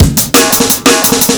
Index of /breakcore is not a good way to get laid/155BPM/silentkillerbreaks
dub.wav